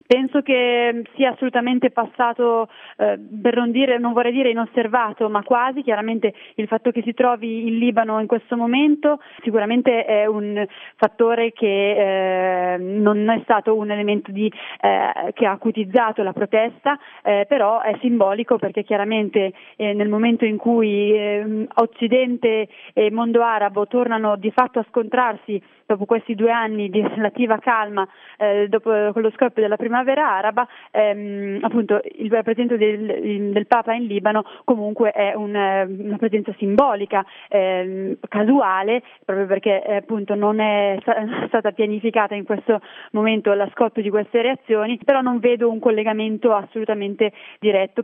intervista
all'interno del Gr delle ore 11, sulla situazione nel mondo arabo, Radio Capital